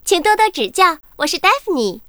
文件 文件历史 文件用途 全域文件用途 Daphne_tk_01.ogg （Ogg Vorbis声音文件，长度0.0秒，0 bps，文件大小：29 KB） 源地址:游戏语音 文件历史 点击某个日期/时间查看对应时刻的文件。